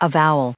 added pronounciation and merriam webster audio
444_avowal.ogg